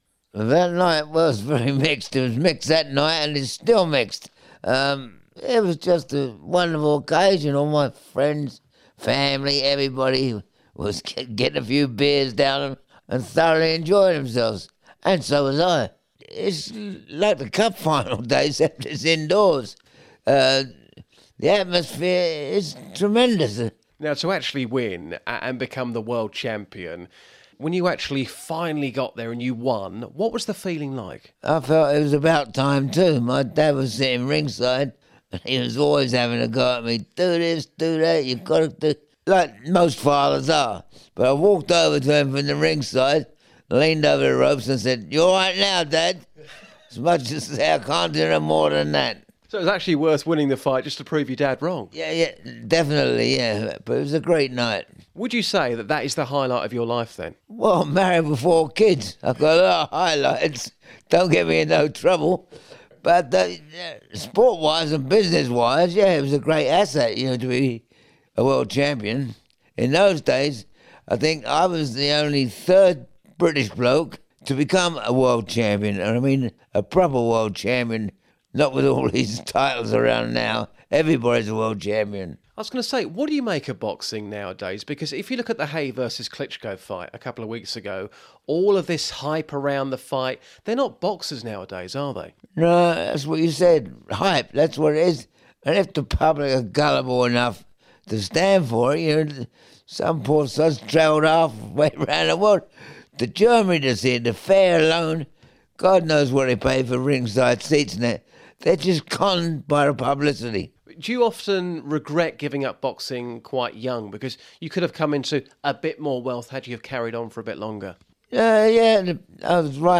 Legendary boxer Terry Downes talks